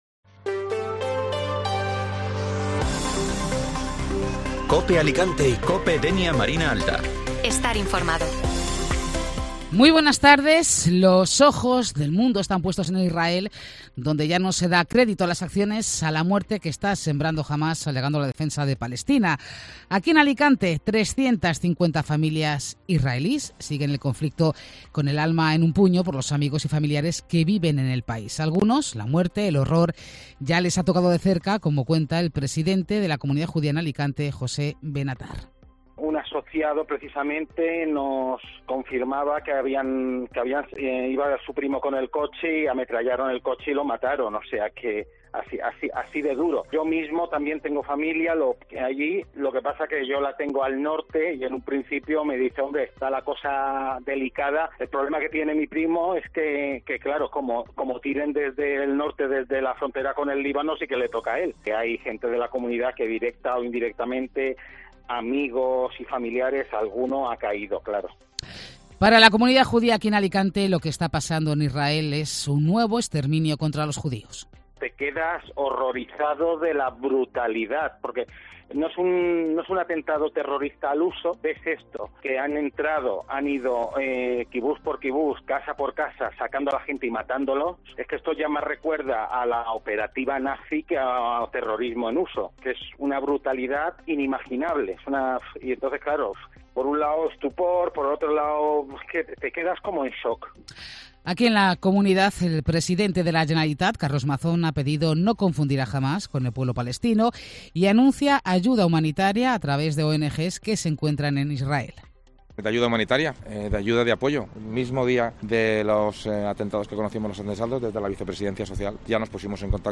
Informativo Mediodía Cope Alicante (Miércoles 11 de Octubre)